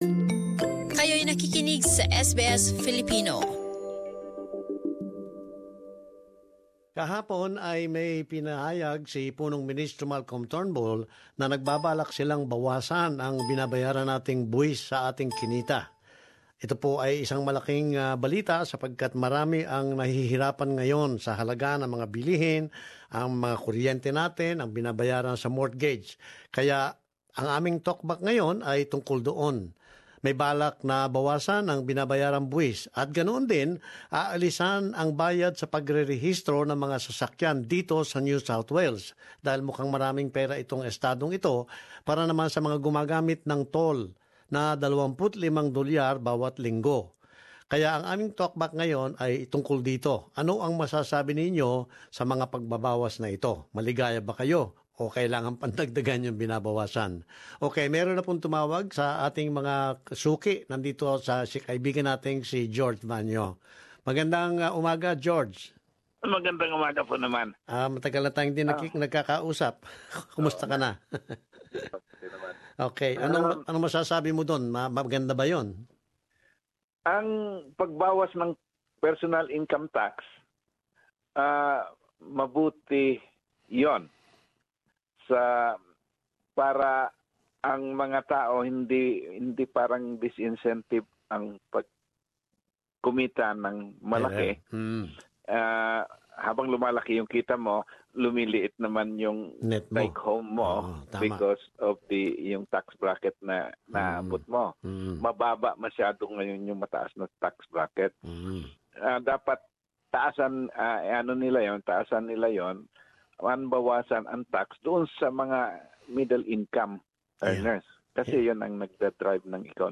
Talkback: Pagbabawas ng buwis sa mga 'middle-income earners' sa Australya?